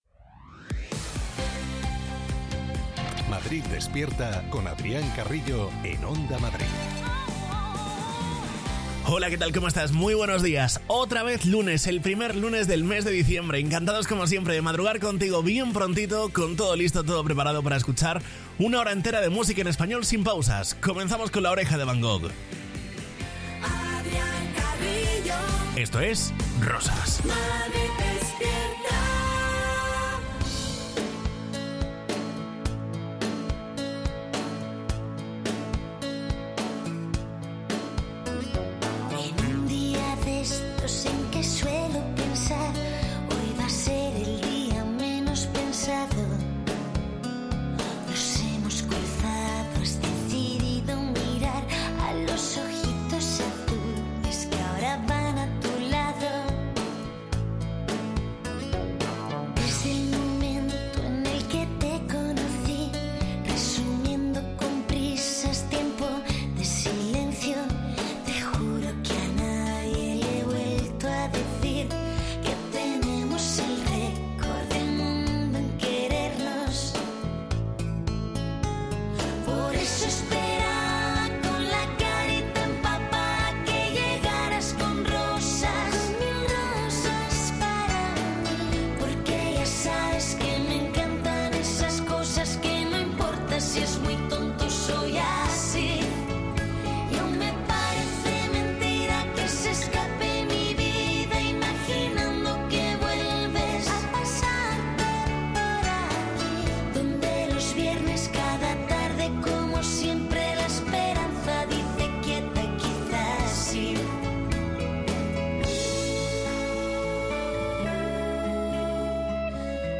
Morning show
para despertar a los madrileños con la mejor música y la información útil para afrontar el día.